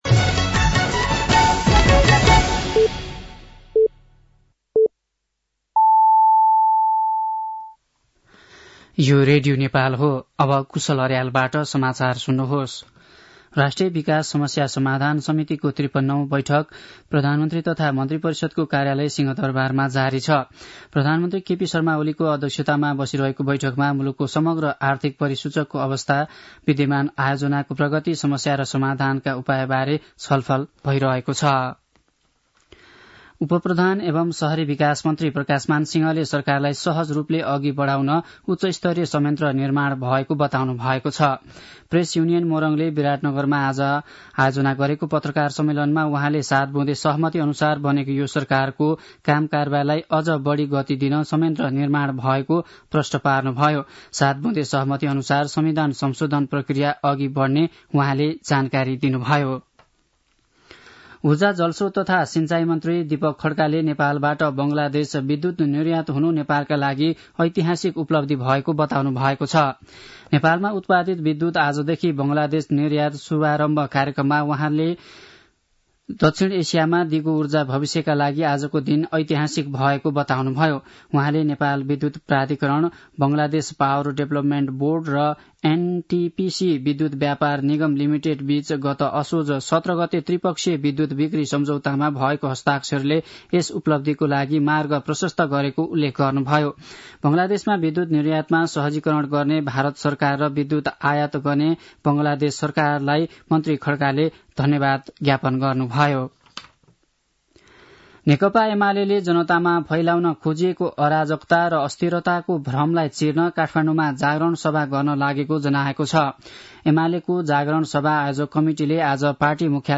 साँझ ५ बजेको नेपाली समाचार : १ मंसिर , २०८१
5-pm-nepali-news-7-30.mp3